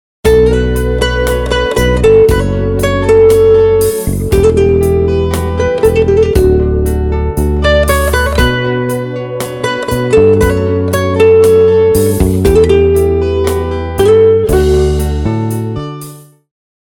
رینگتون محزون و بی کلام
برداشتی آزاد از موسیقی های بی کلام خارجی